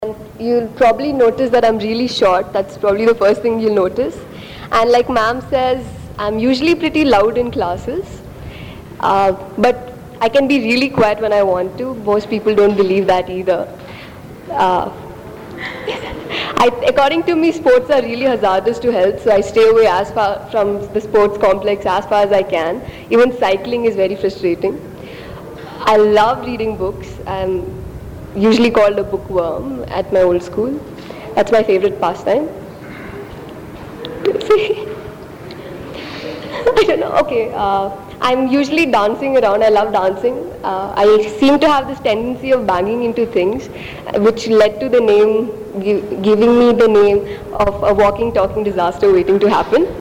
Dialects of English: Indian English